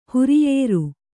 ♪ huriyēru